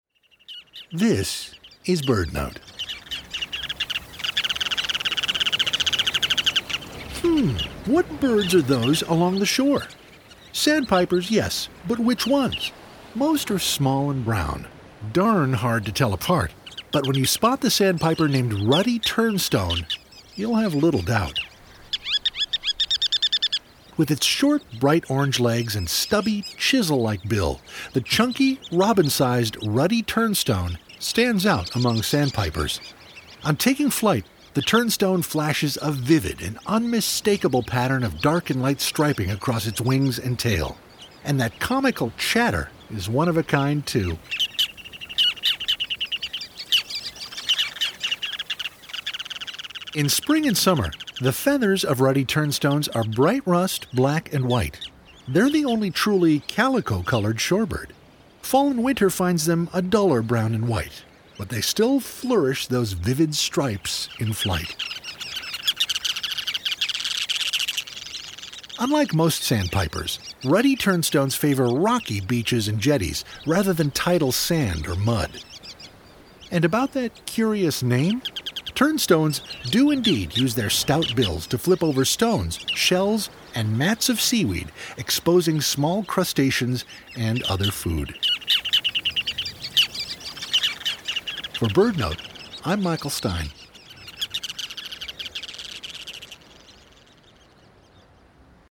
And that comical chatter is one of a kind too.